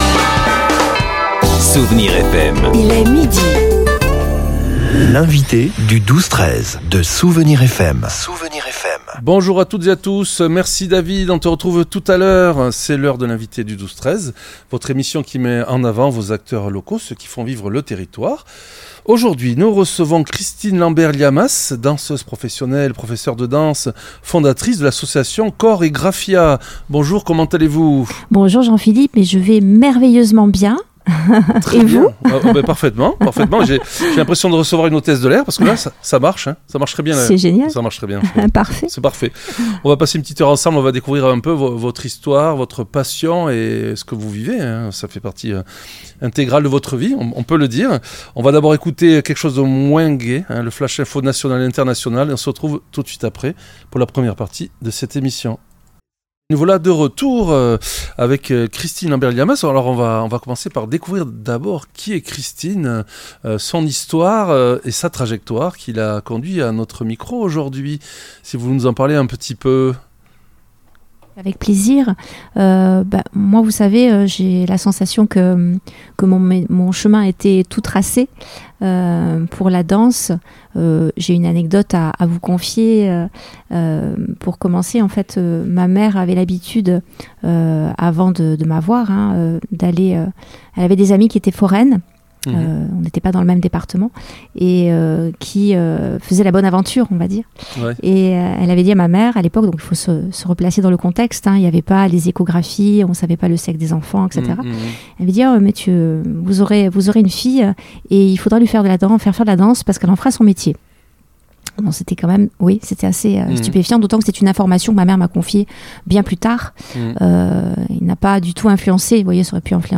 L'entretien